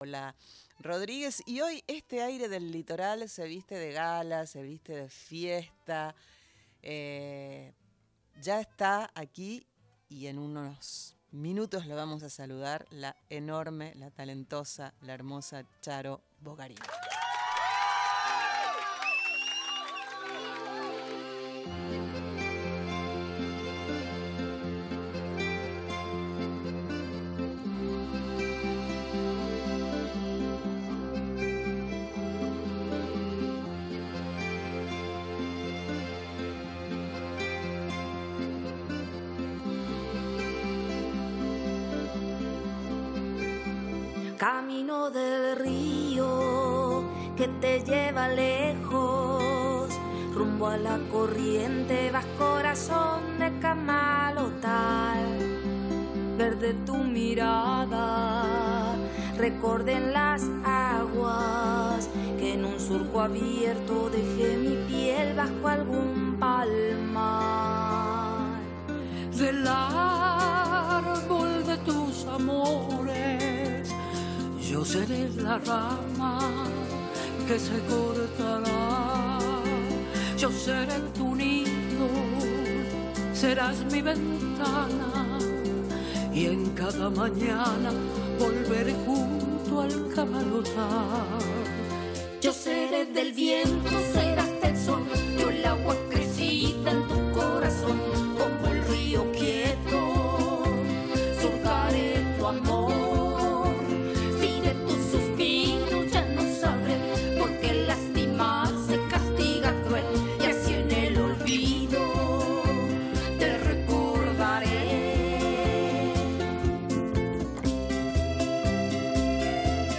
VISITA EN PISO